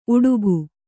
♪ uḍugu